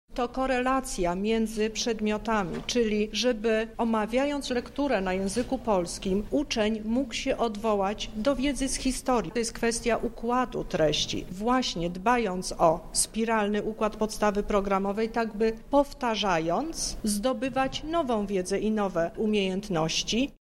O najważniejszych zmianach jakie czekają uczniów mówi Teresa Misiuk – lubelski kurator oświaty: